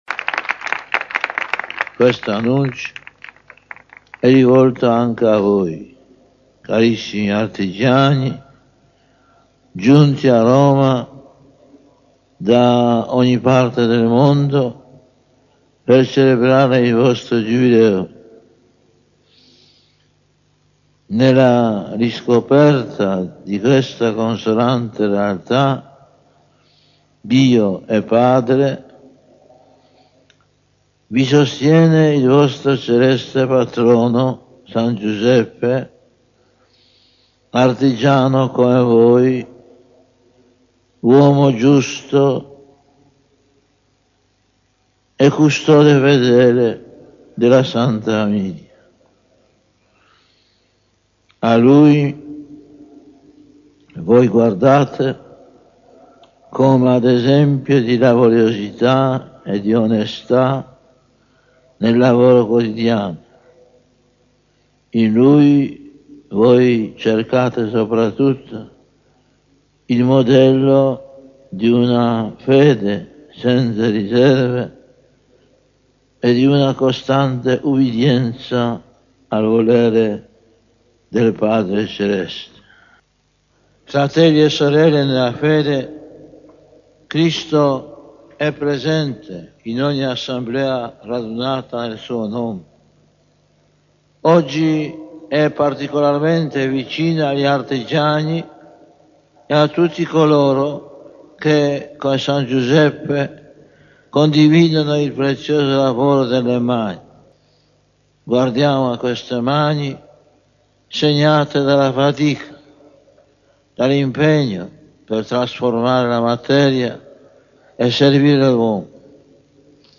PAPA GIOVANNI PAOLO II in occasione del “Giubileo degli artigiani”, a Roma il 19.03.2000.
papa_giovanni_paolo_ii_giubileo.mp3